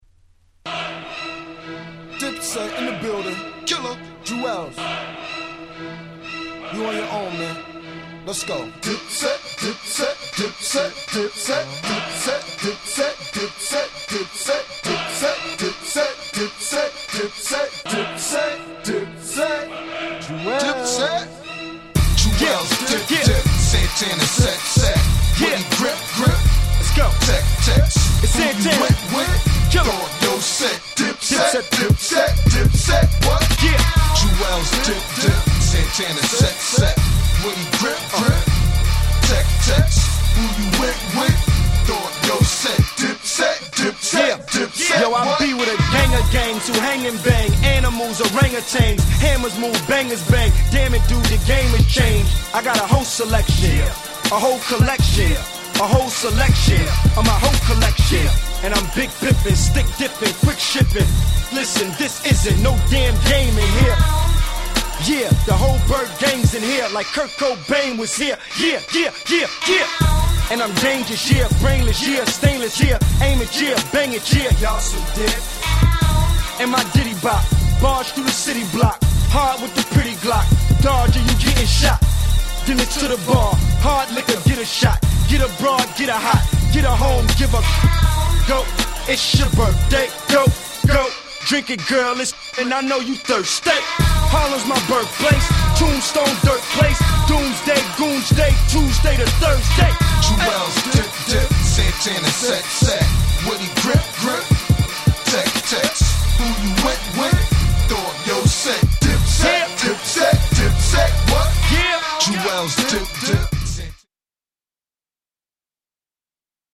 03' Big Hit Hip Hop !!!!!